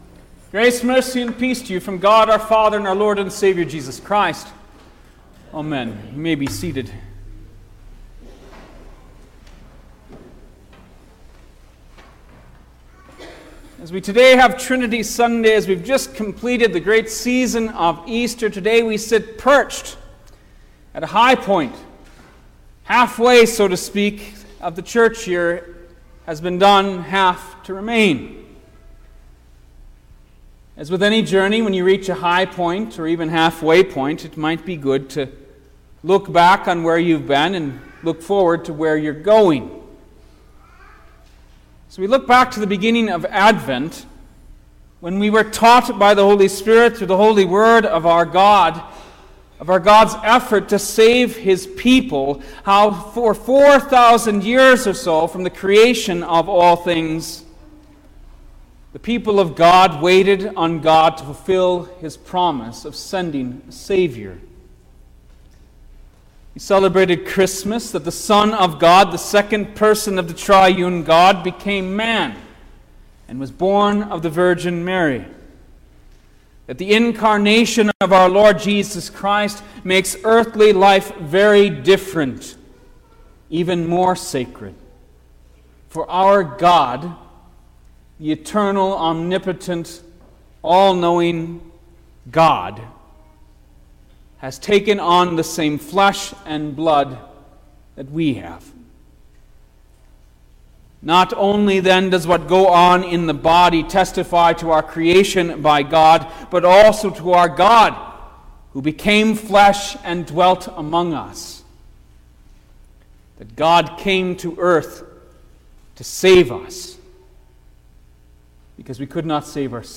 June-4_2023_The-Holy-Trinity_Sermon-Stereo.mp3